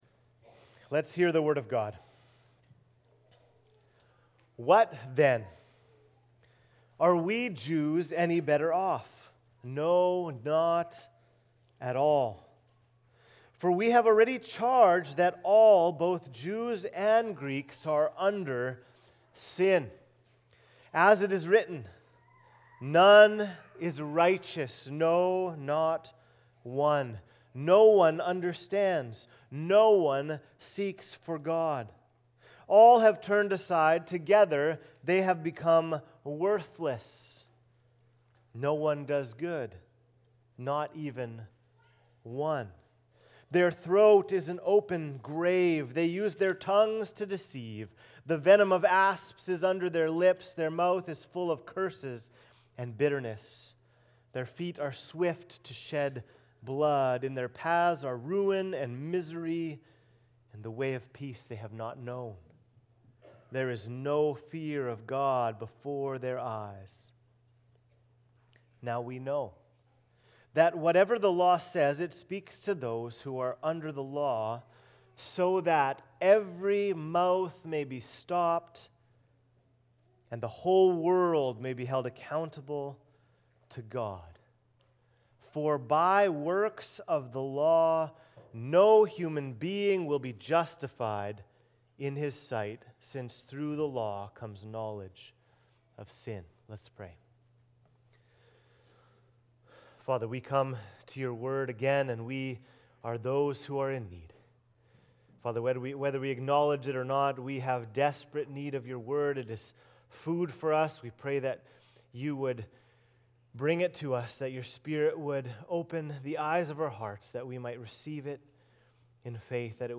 Sermons | Cornerstone Bible Church